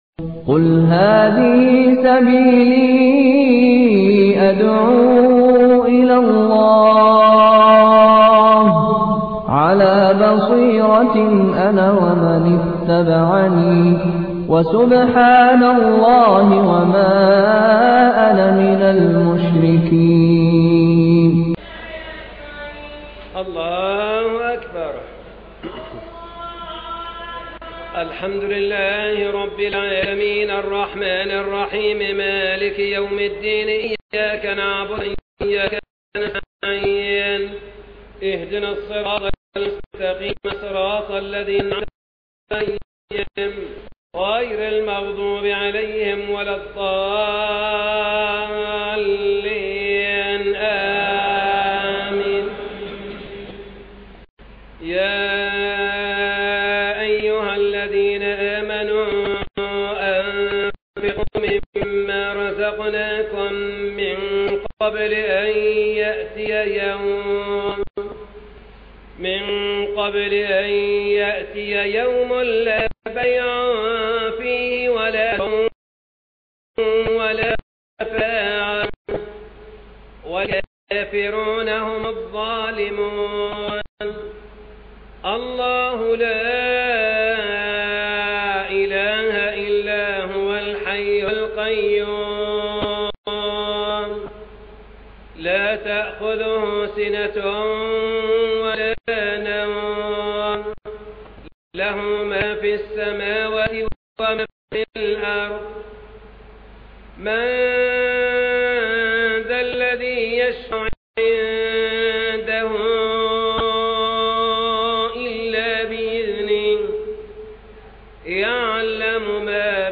صلاة التراويح من المسجد الأقصى (ليلة 28 رمضان) 1432 هـ - قسم المنوعات